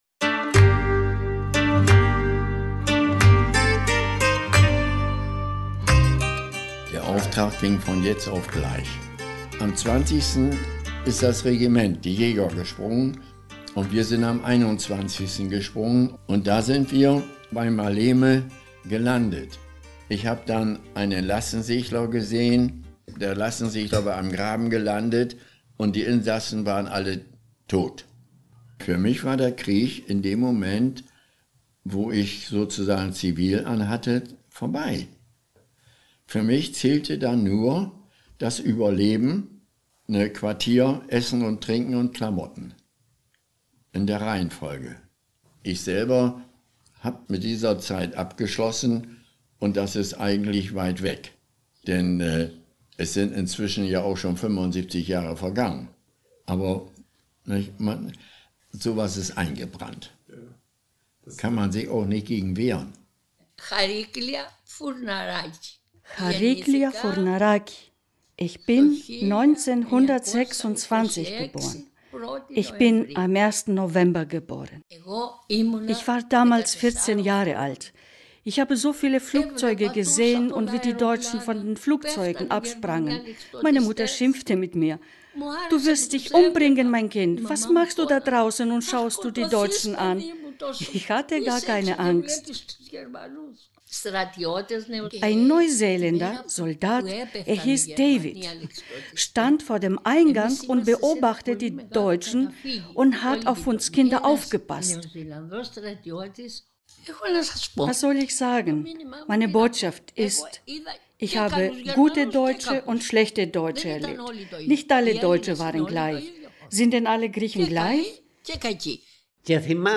Zu Wort kommen vier Angehörige der Kriegsgeneration. Drei Griechen und ein Deutscher berichten, was sie erlebt haben – während der Schlacht, unter der Besatzungsherrschaft, im Widerstand.